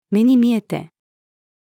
目に見えて-female.mp3